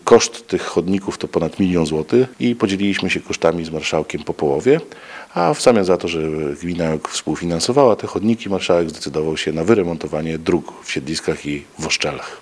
– Ruszają też prace, związane z wymianą chodników w Siedliskach i Woszczelach – mówi wójt gminy Ełk, Tomasz Osewski.